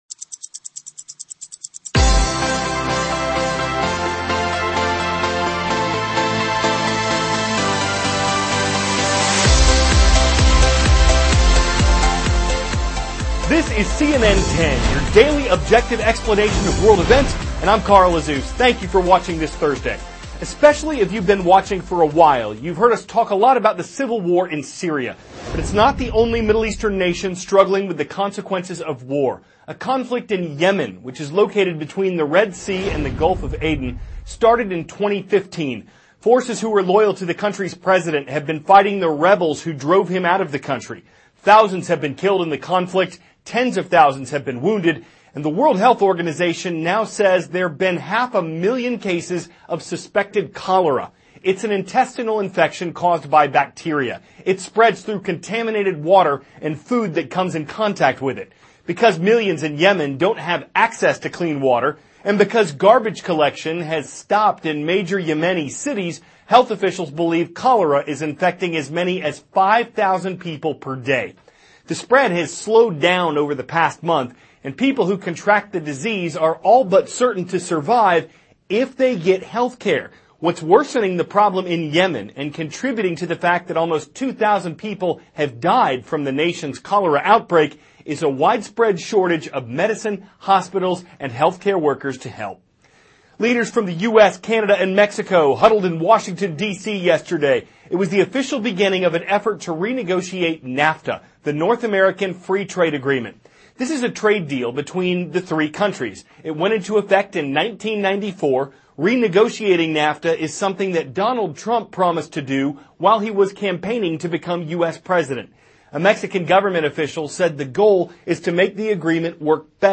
CARL AZUZ, cnn ANCHOR: This is cnn 10, your daily objective explanation of world events.